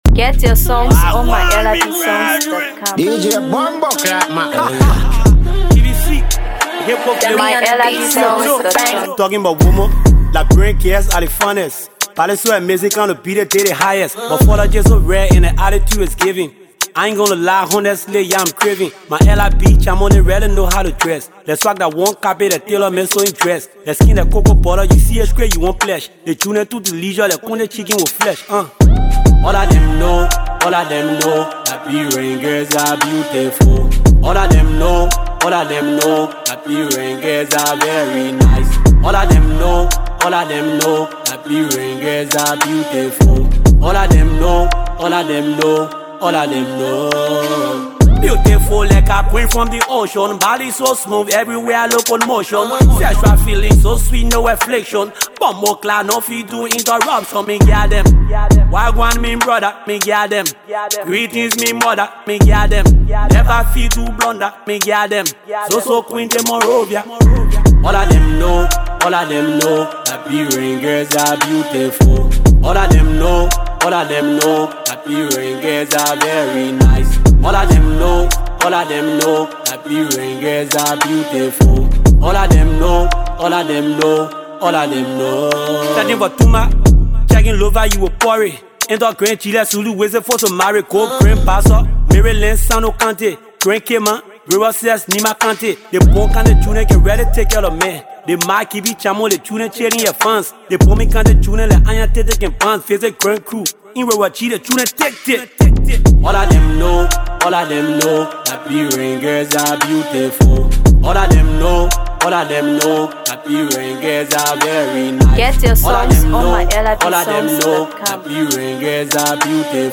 Afro PopHipcoMusic